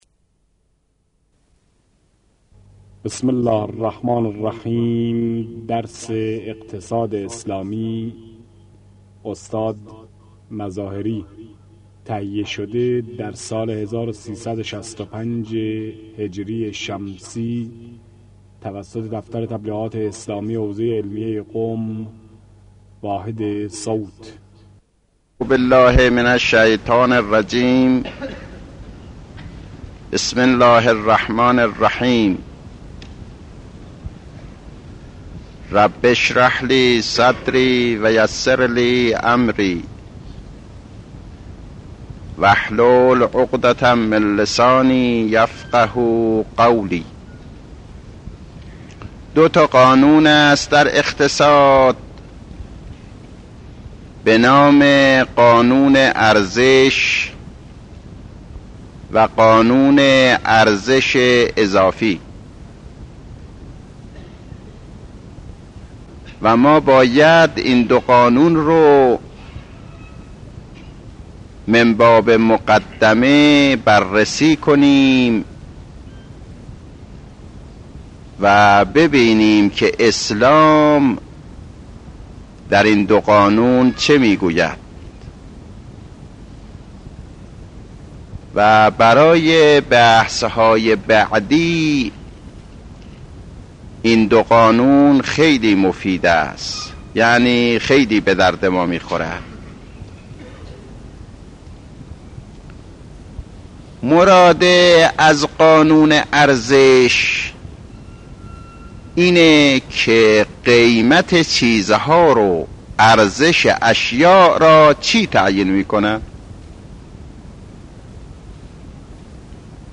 آيت الله مظاهري - اقتصاد اسلامي | مرجع دانلود دروس صوتی حوزه علمیه دفتر تبلیغات اسلامی قم- بیان